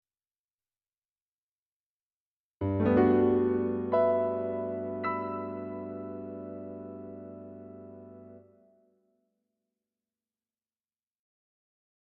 03 02を少しゆっ くり 00.12